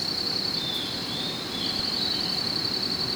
Call recorded PROCOSARA, PN San Rafael